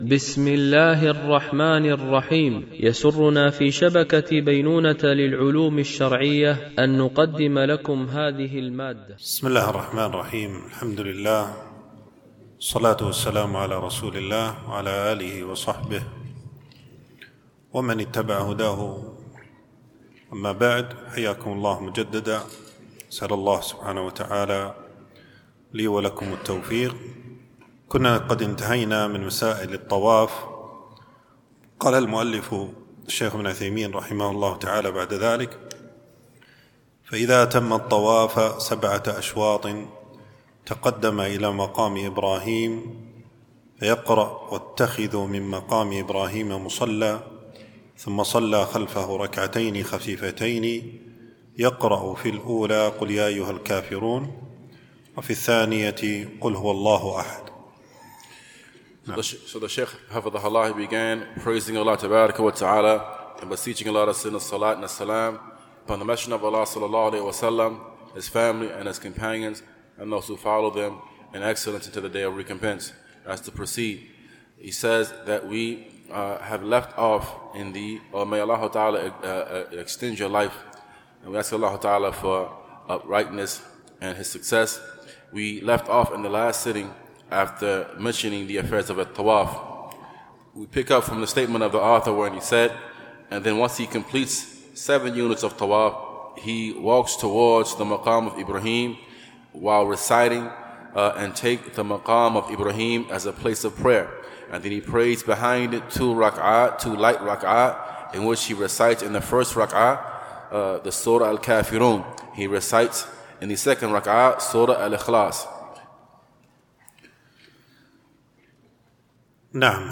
بمسجد أم المؤمنين عائشة رضي الله عنها